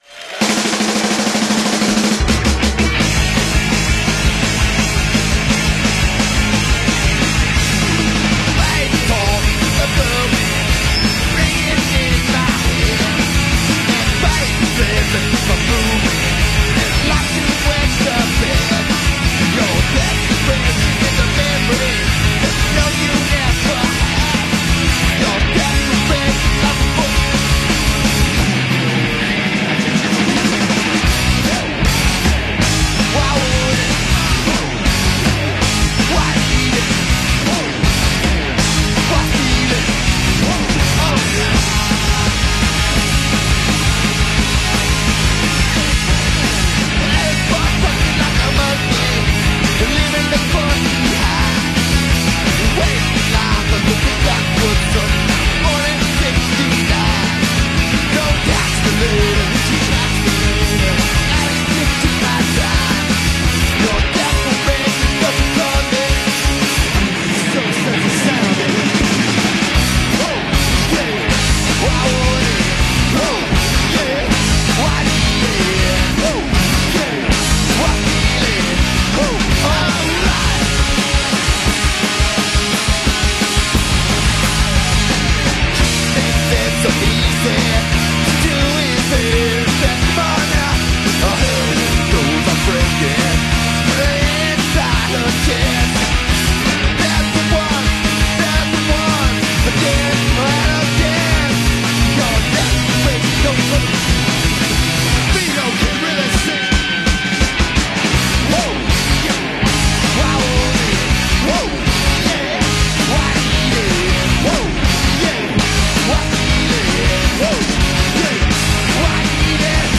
live at the Reading Festival, 1996.